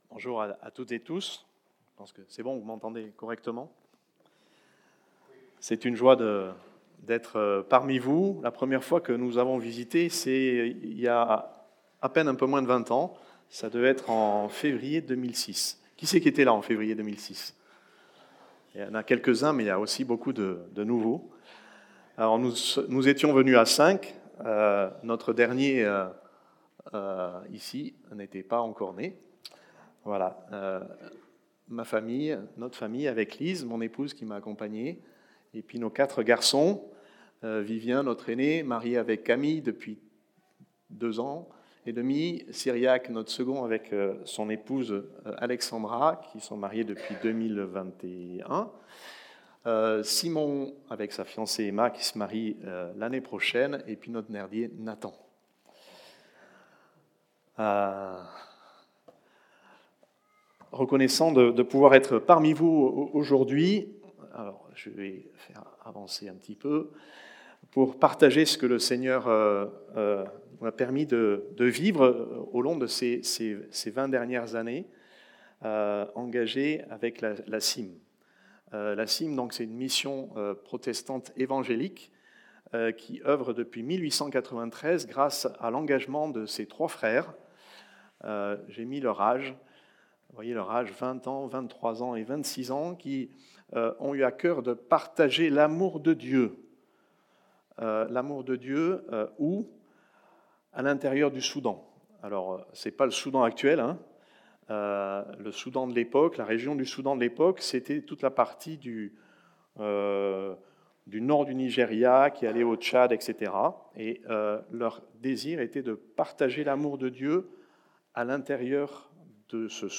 Culte du dimanche 30 Novembre 25
Prédications